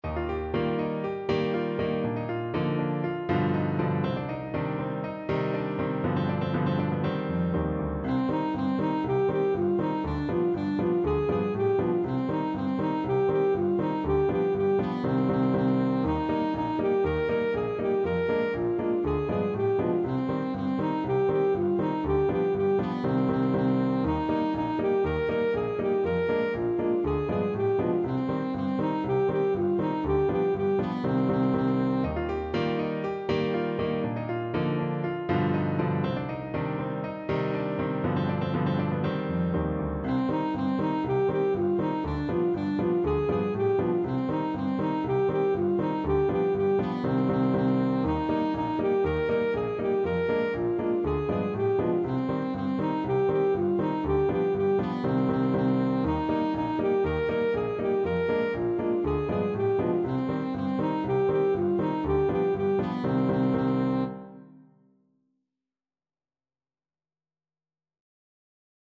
Alto Saxophone version
Alto Saxophone
4/4 (View more 4/4 Music)
Allegro (View more music marked Allegro)
Traditional (View more Traditional Saxophone Music)
world (View more world Saxophone Music)